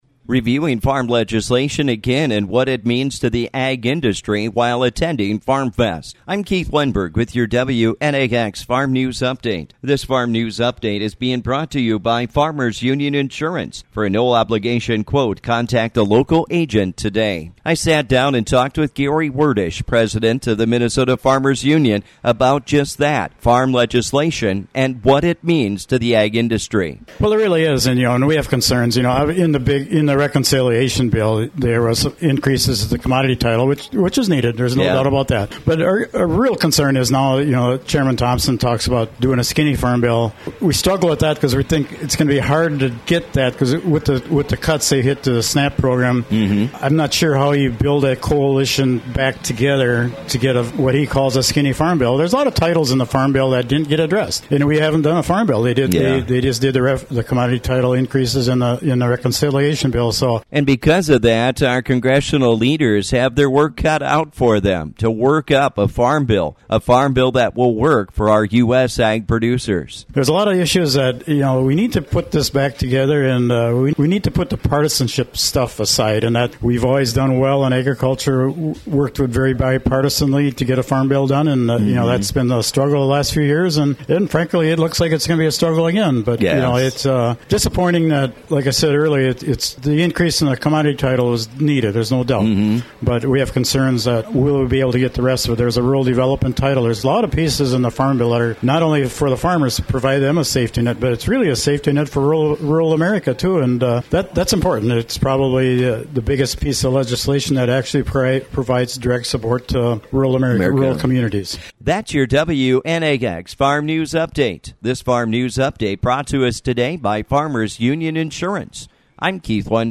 While attending Farm Fest near Redwood Falls, MN; We talk about Farm Legislation and what it means to the Ag Industry with the MN Farmers Union.